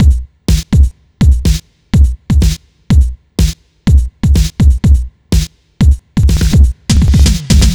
Bleep Hop Bd _ Snr.wav